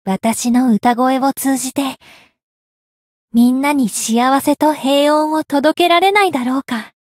灵魂潮汐-密丝特-圣诞节（摸头语音）.ogg